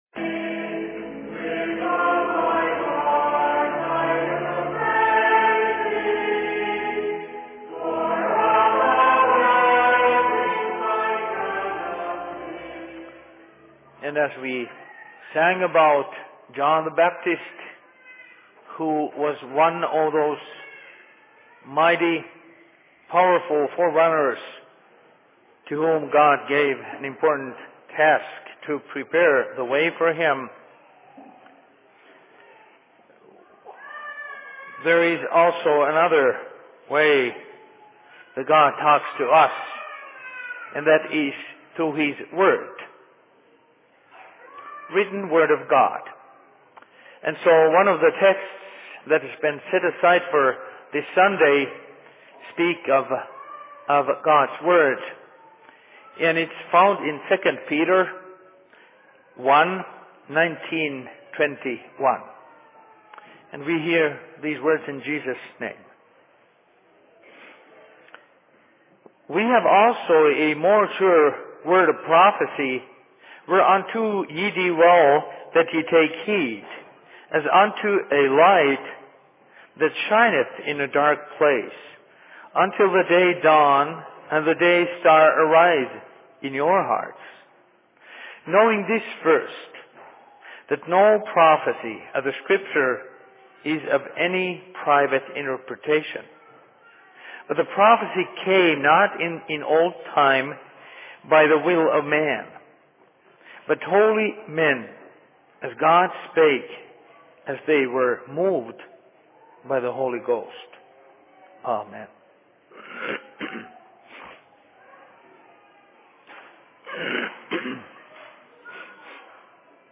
Sermon in Rockford 12.12.2010
Location: LLC Rockford